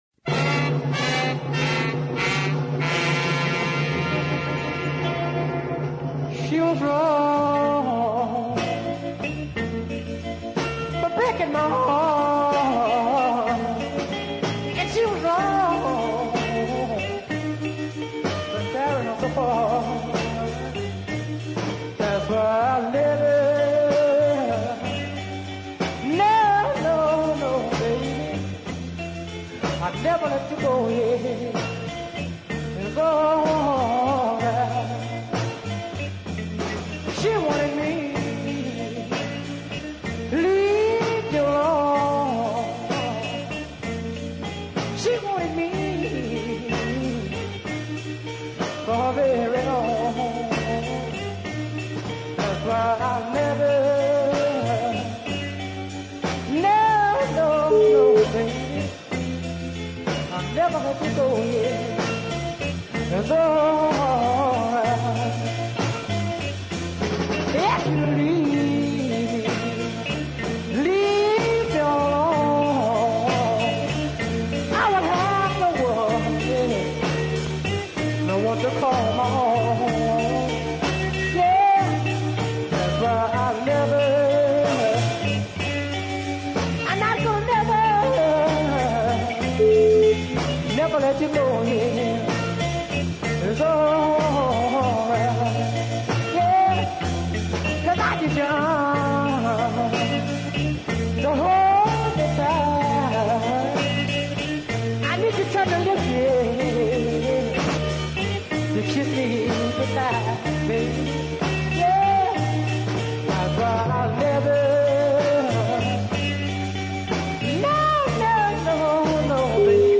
a fine deep side in the grand southern tradition